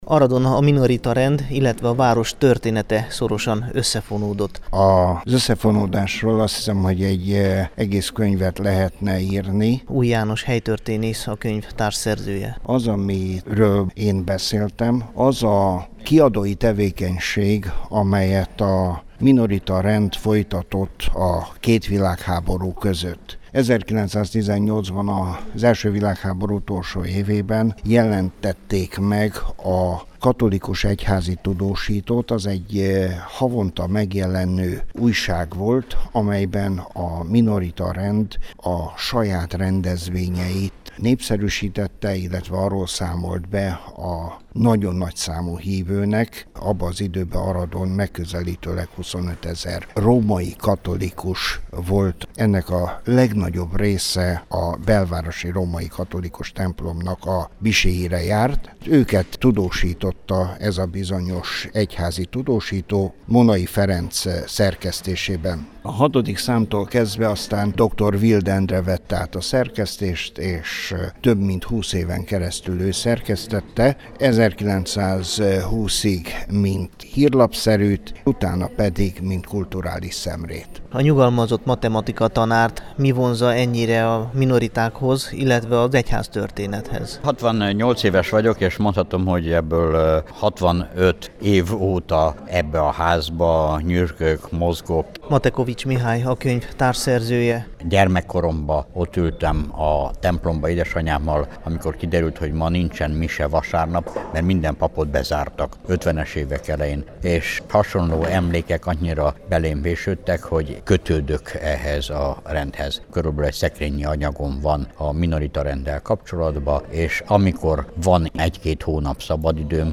A minoriták Aradon című könyvet péntek este mutatták be a Minorita Kultúrházában, népes közönség és egyházi személyiségek jelenlétében.